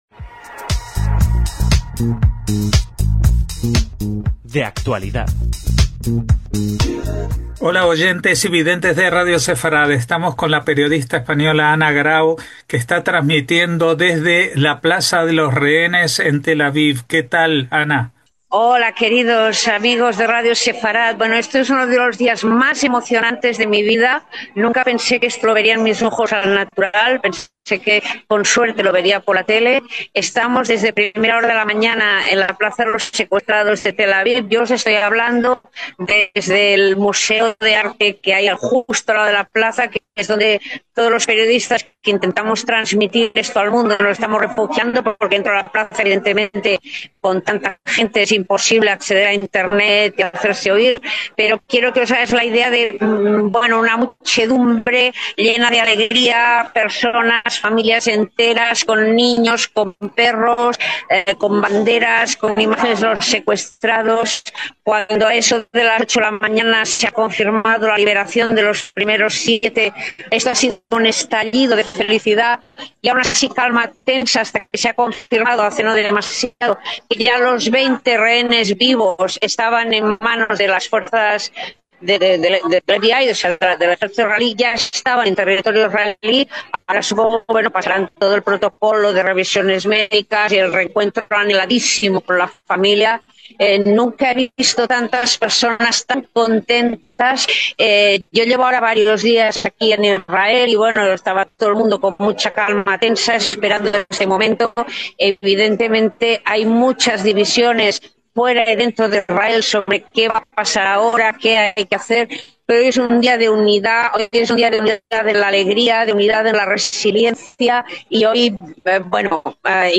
desde la Plaza de los Rehenes de Tel Aviv